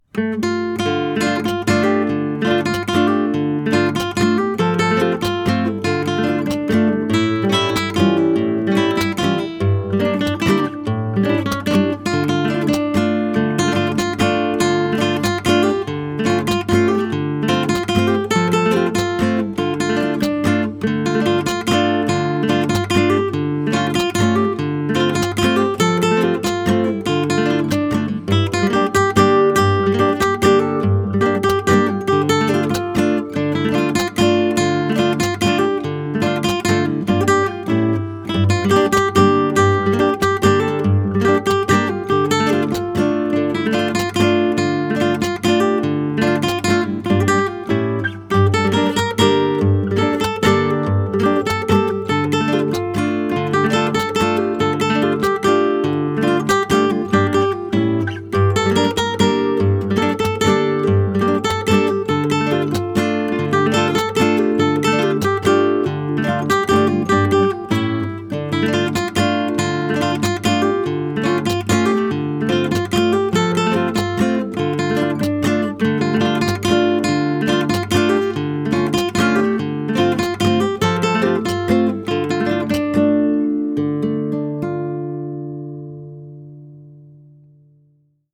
گام قطعهِِDm
متر قطعه6/8
این قطعه در متر شش و هشت و در گام  D مینور نگارش شده.
برای گیتار